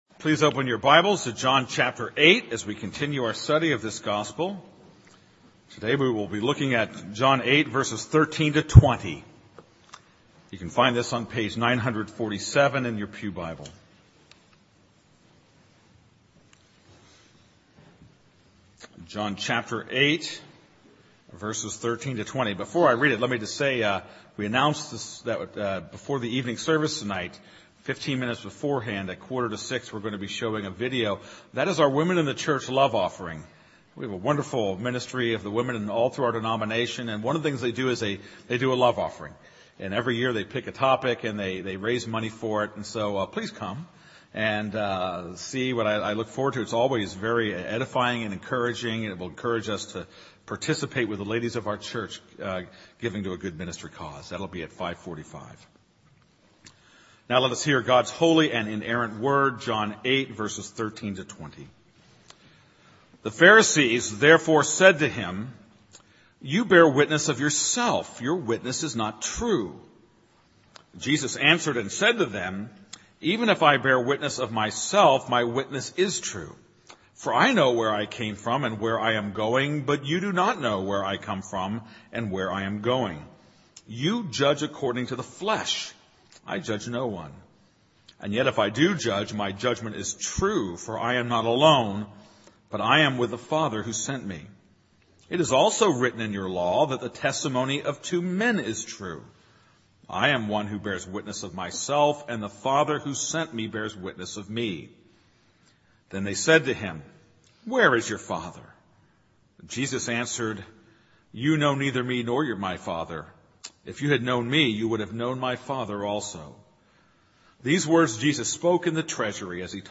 This is a sermon on John 8:13-20.